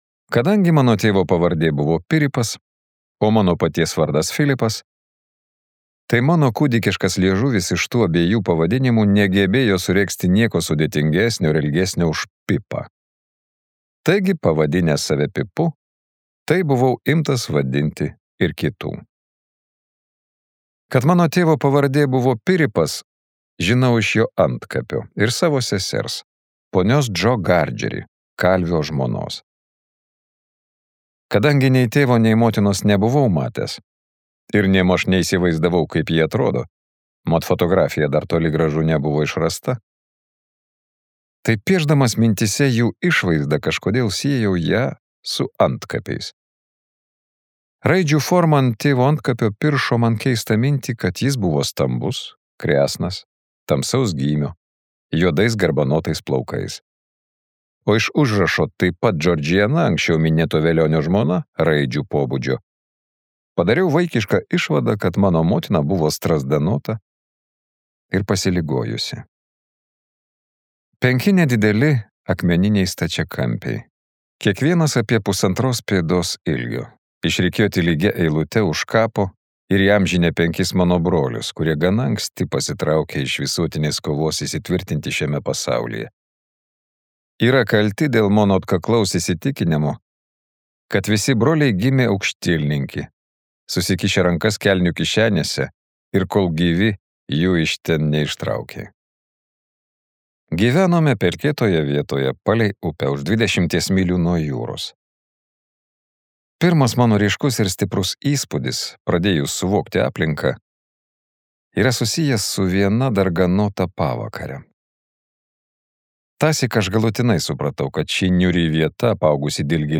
Charles Dickens audioknyga „Didieji lūkesčiai“ yra literatūros klasika, atgimusi